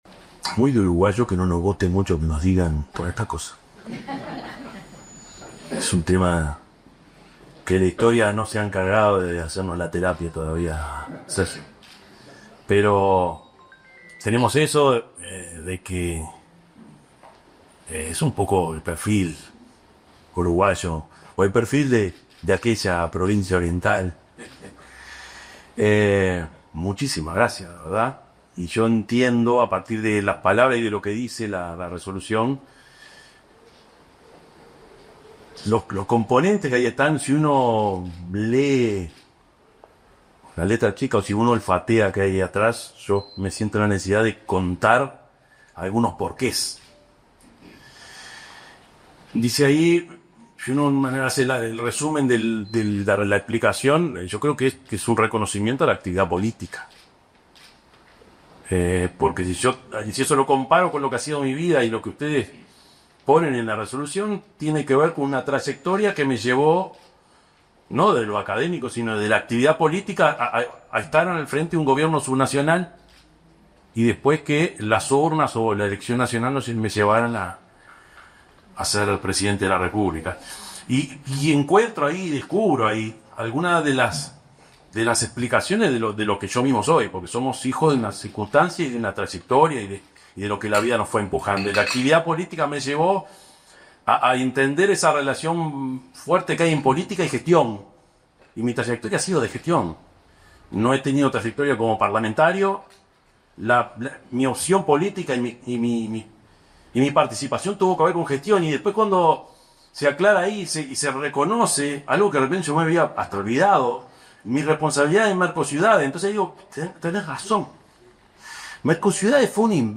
Palabras del presidente Yamandú Orsi en Universidad del Congreso
El presidente de la República, Yamandú Orsi, recibió la condecoración de doctor “honoris causa” de la Universidad de Congreso de Mendoza.